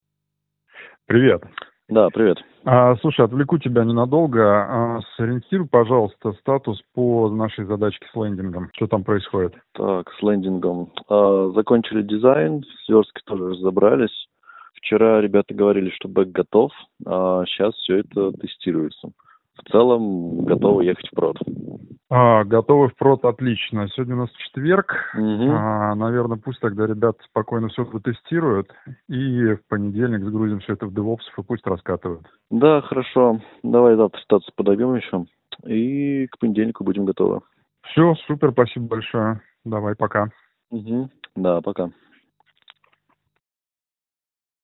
Пример 1: улица + транспорт
Результат с AI TelcoMixer: слышна речь без гула и машин
noise-no-highway-1.mp3